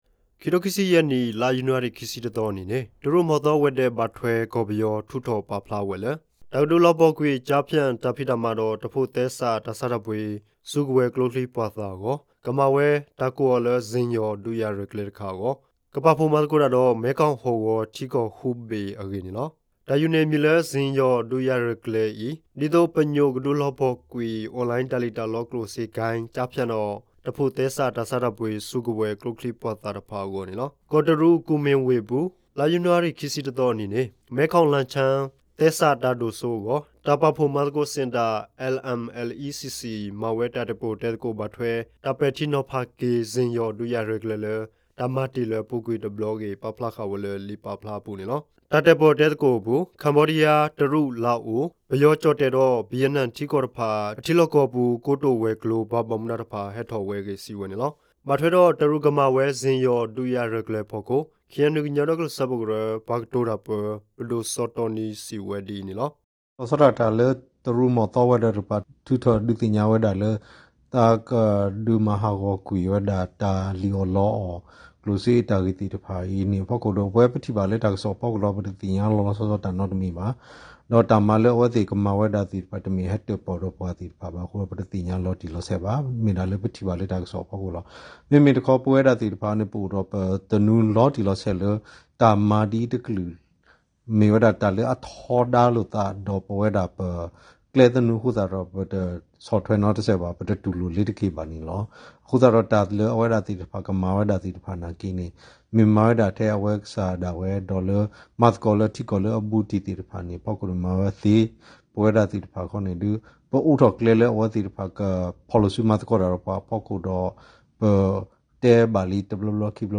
Radio တၢ်ကစီၣ် ကွီၢ်မ့ၣ်တၢ်မူၤတၢ်ရၤ တၢ်ကစီၣ်